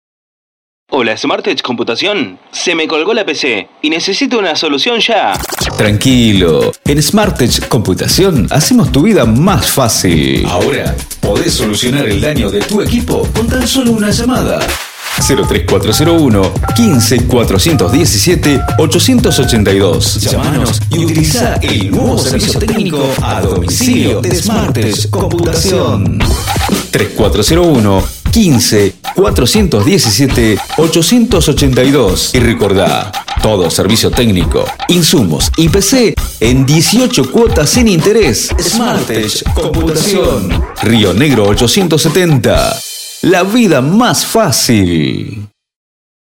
SPOT SMARTTECH COMPUTACION